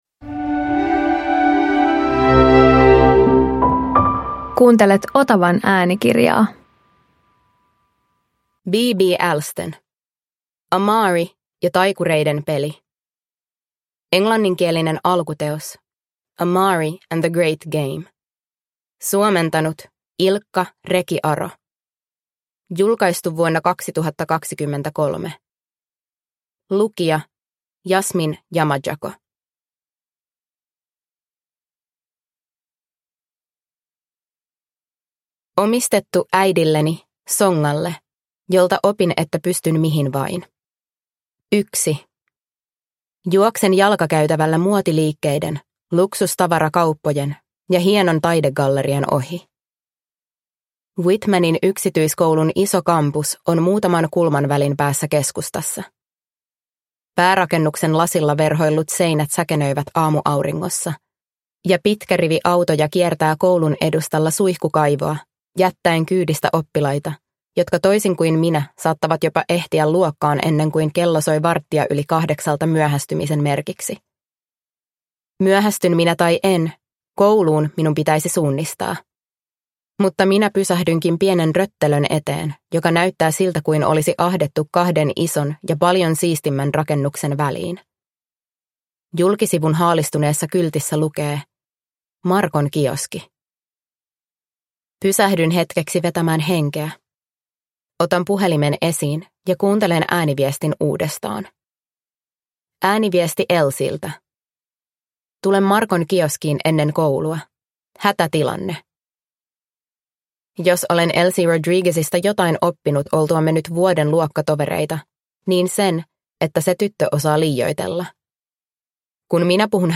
Amari ja taikureiden peli – Ljudbok – Laddas ner